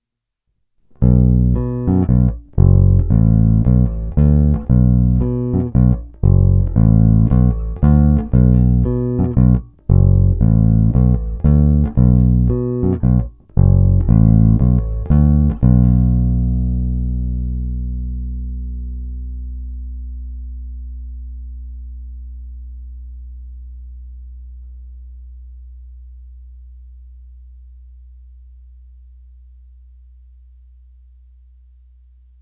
Má hodně výrazné středy, je poměrně agresívní, kousavý.
Není-li uvedeno jinak, následující ukázky byly provedeny rovnou do zvukové karty a s plně otevřenou tónovou clonou, jen normalizovány, jinak ponechány bez úprav.
Hra nad snímačem